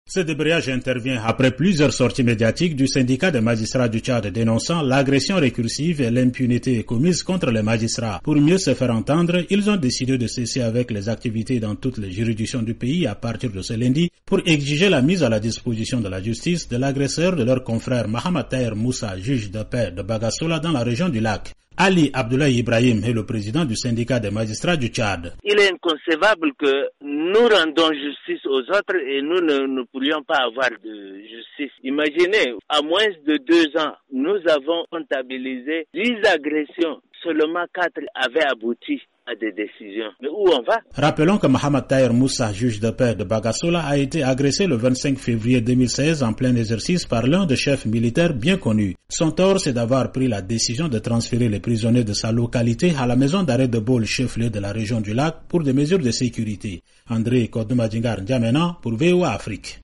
Compte-rendu